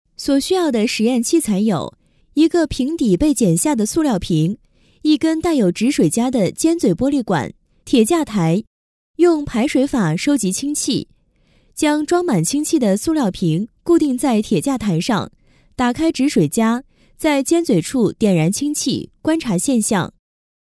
氢气爆炸.mp3